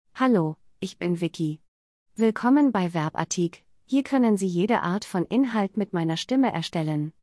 Vicki — Female German AI voice
Vicki is a female AI voice for German.
Voice sample
Listen to Vicki's female German voice.
Female